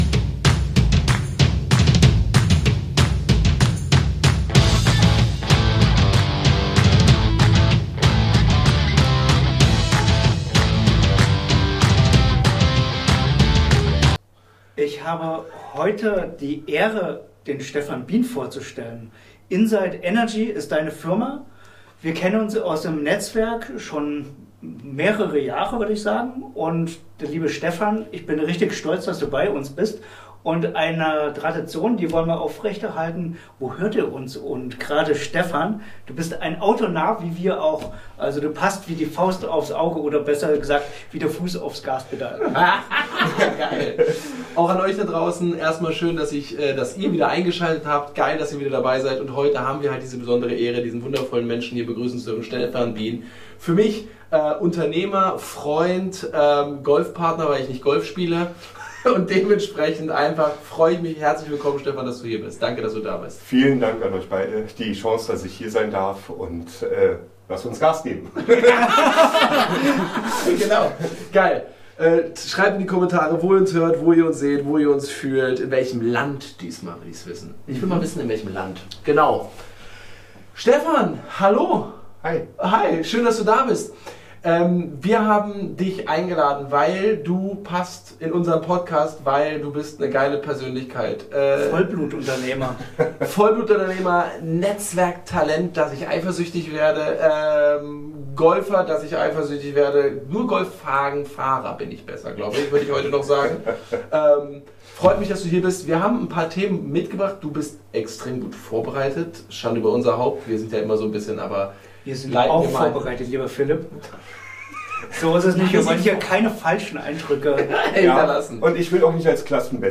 Warum persönliche Begegnungen oft mehr bewirken als jede Kampagne Wie Netzwerken strategisch zum Aufbau einer starken Marke beiträgt Tipps für Unternehmer:innen, die ihr Netzwerk gezielt auf- und ausbauen wollen Ein Gespräch über Menschen, Energie – und die Kraft von Beziehungen im Business.